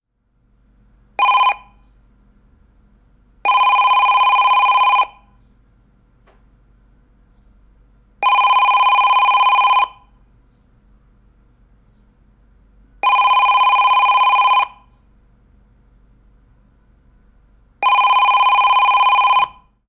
Tono de un teléfono fijo 04
tono
teléfono
Sonidos: Oficina